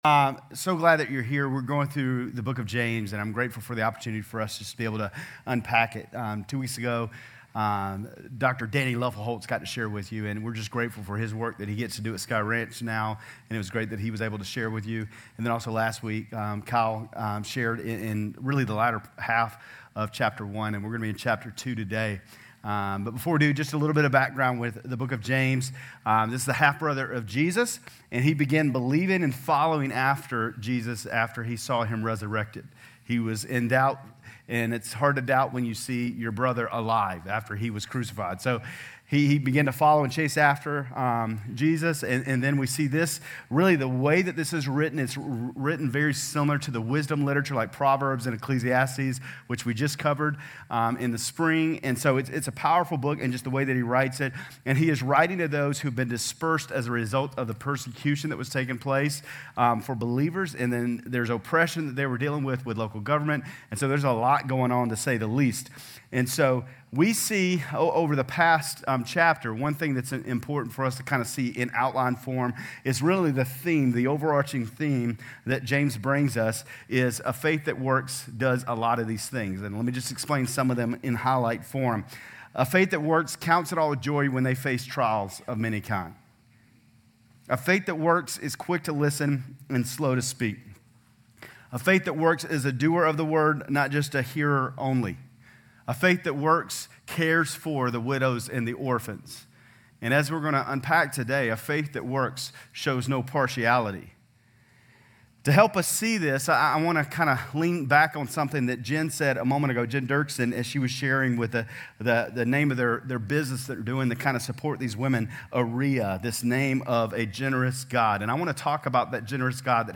Grace Community Church Lindale Campus Sermons James 2:1-13 Jun 25 2023 | 00:25:24 1x 00:00 / 00:25:24 Subscribe Share RSS Feed Share Link Embed